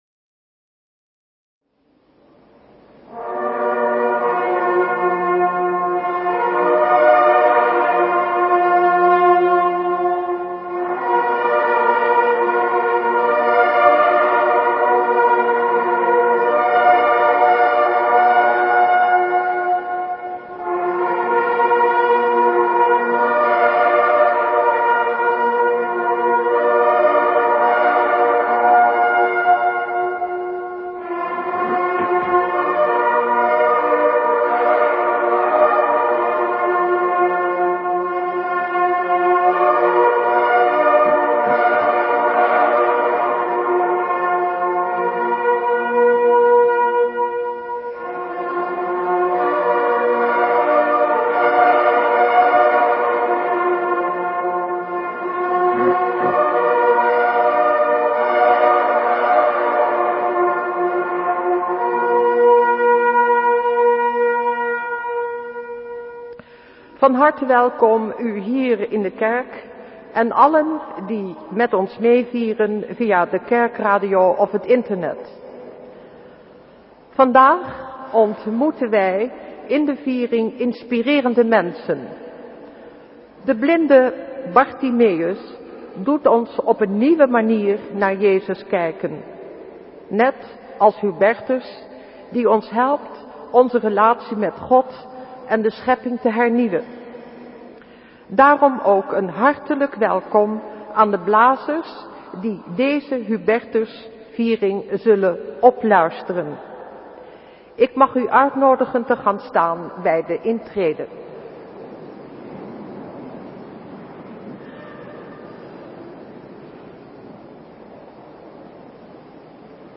Lezingen
Eucharistieviering beluisteren vanuit de Goede Herder te Wassenaar (MP3)